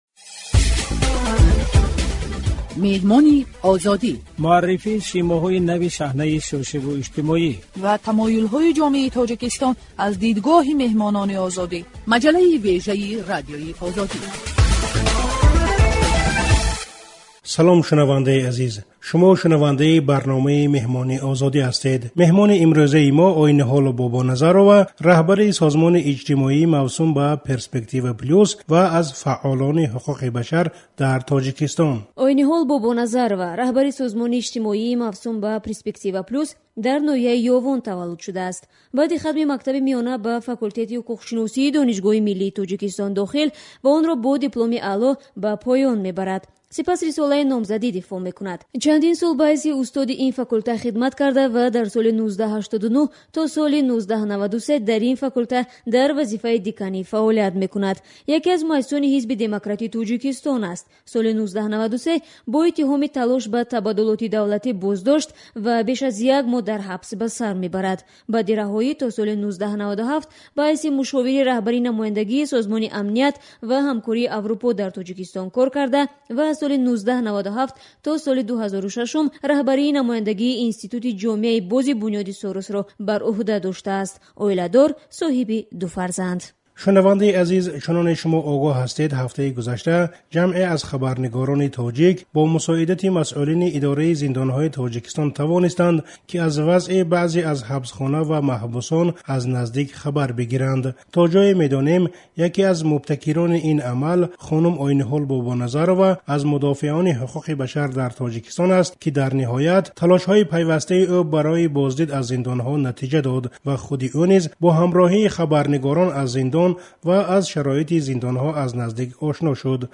Мусоҳибаи